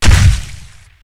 large_step1.ogg